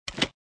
ui_dialogclick.mp3